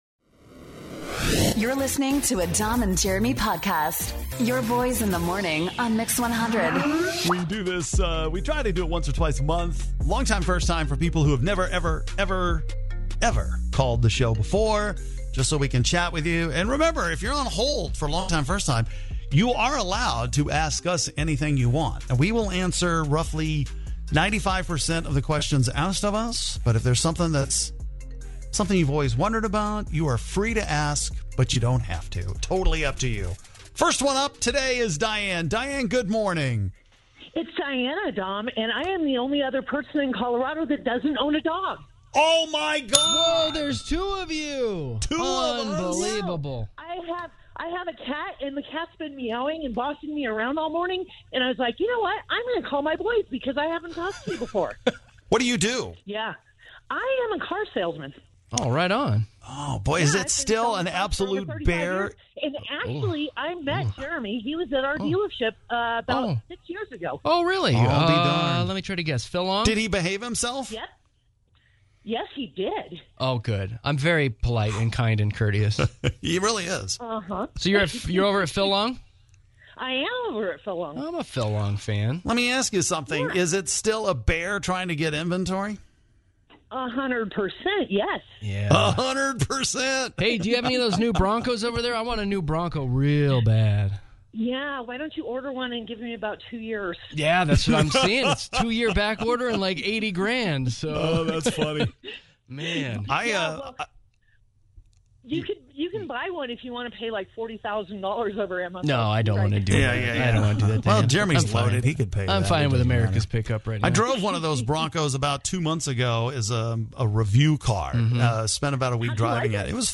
A FANTASTIC new batch of listeners that called in today - that have NEVER called the show before.